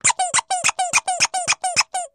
Baby Toy Squeeze Toy, Several Squeezes